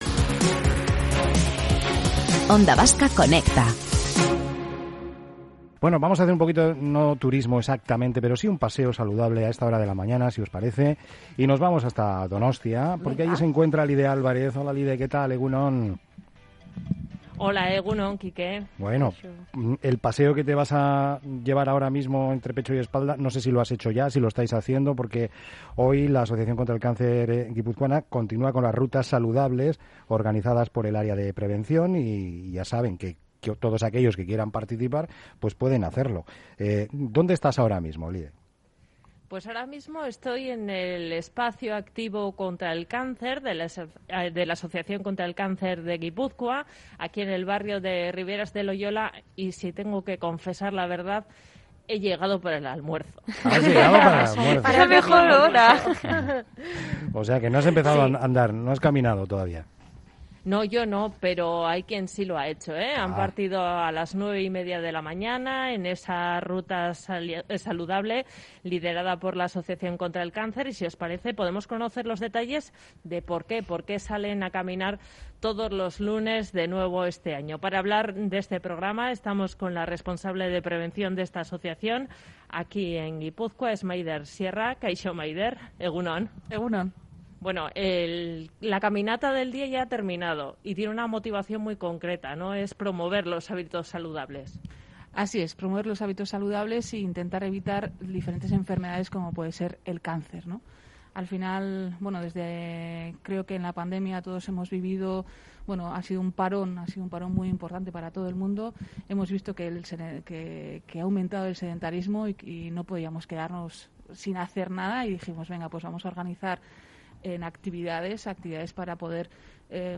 Entrevistada en Onda Vasca